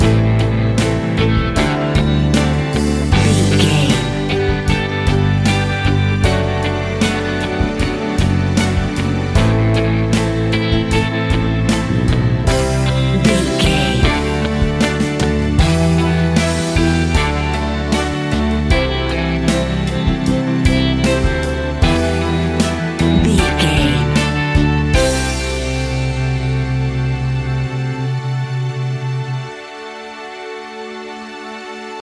Pop Rock Anthem 30 Seconds.
Aeolian/Minor
pop rock instrumentals
happy
upbeat
bouncy
drums
bass guitar
electric guitar
keyboards
hammond organ
acoustic guitar
percussion